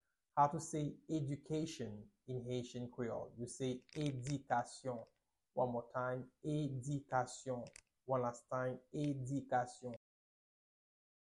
Pronunciation:
2.How-to-say-Education-in-haitian-creole-–-Edikasyon-pronunciation.mp3